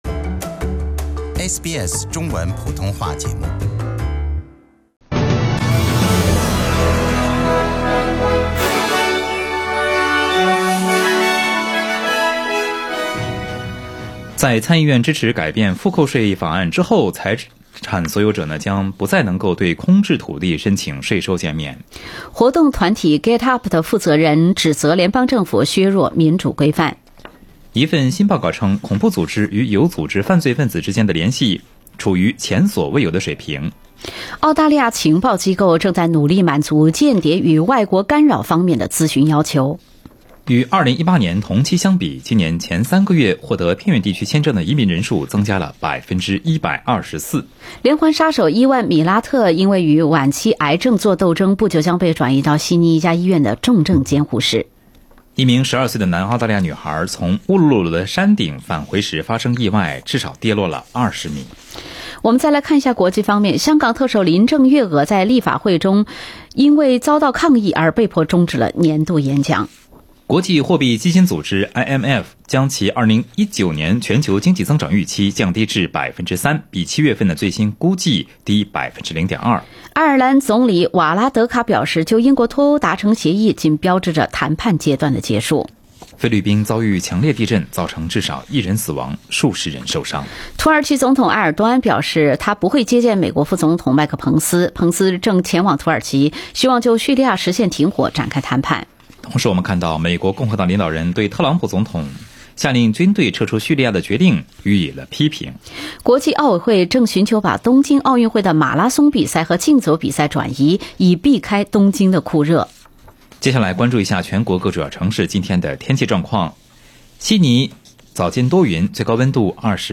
SBS早新闻（10月17日）